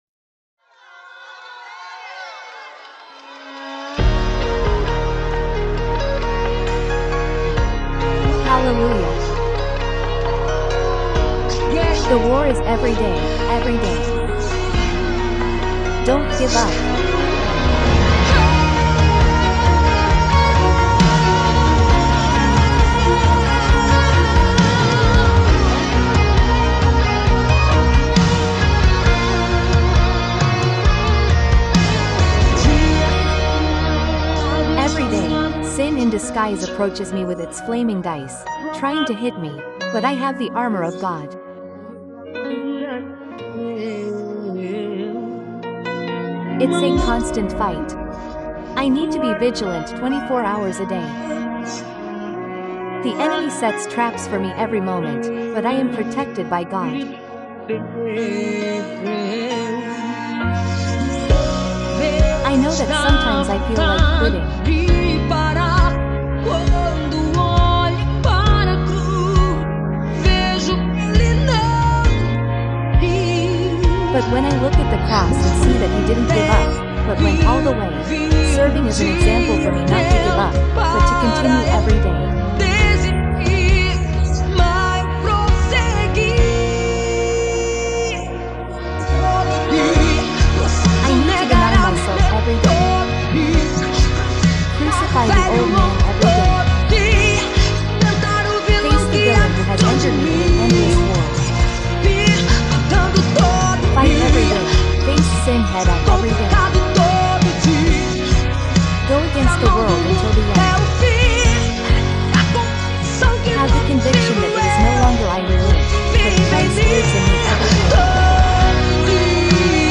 Universal Gospel
The passionate and impactful gospel music artiste